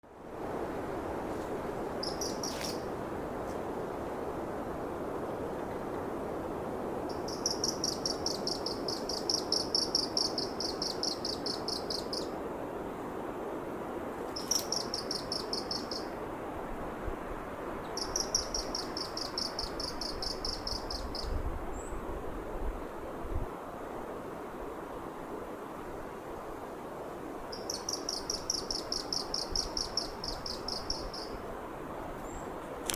Putni -> Pūces ->
Apodziņš, Glaucidium passerinum
Administratīvā teritorijaPāvilostas novads